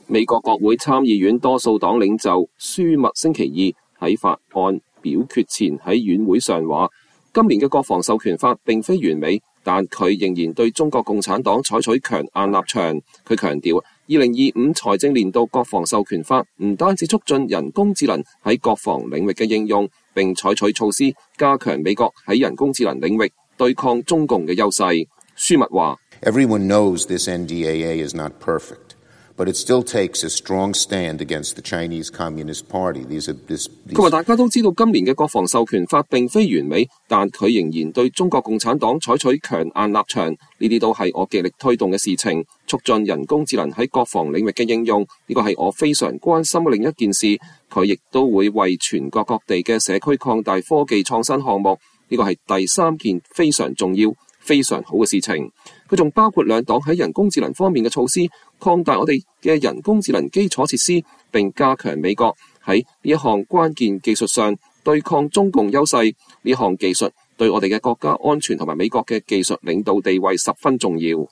美國國會參議院多數黨領袖查克·舒默(Chuck Schumer)週二在法案表決前在院會上說，“今年的《國防授權法》並非完美，但它仍然對中國共產黨採取了強硬的立場”。他强調，《2025財政年度國防授權法》不僅促進人工智能在國防領域的應用，並採取措施加強美國在人工智能領域對抗中共的優勢。